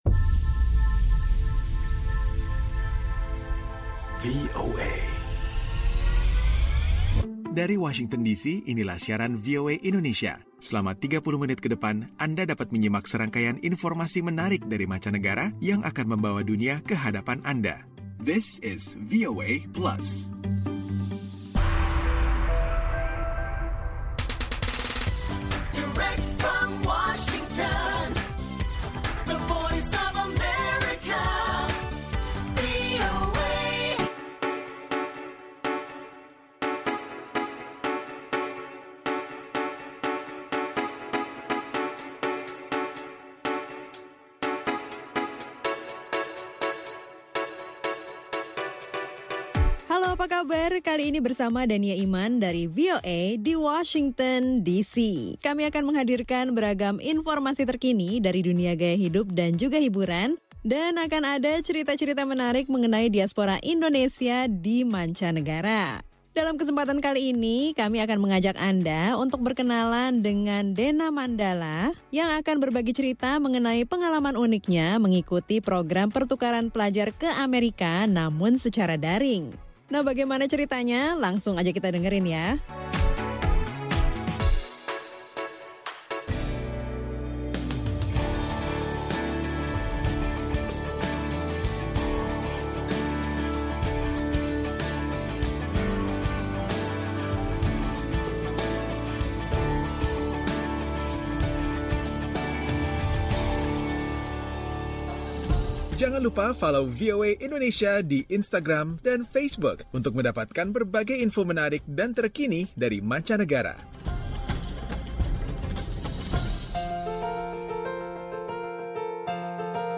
VOA Plus kali ini mengajak Anda menyimak obrolan bersama seorang mahasiswa Indonesia asal Bali yang akan berbagi cerita seputar perjalanan hidupnya dalam meraih pendidikan, salah satunya adalah dengan cara mengikuti program pertukaran pelajar ke Amerika.